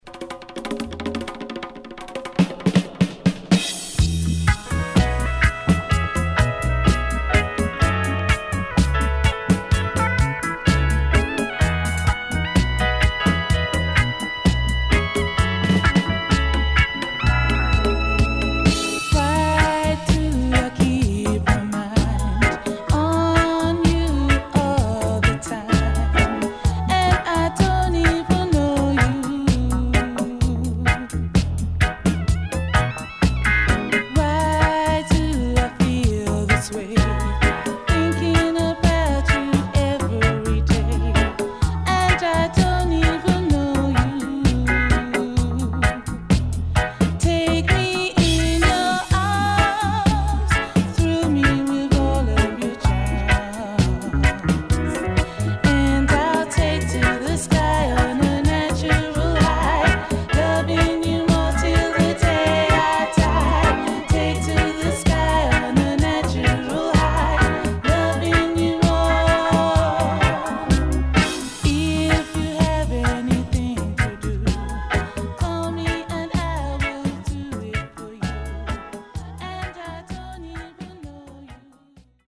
Great Lovers Vocal & Dubwise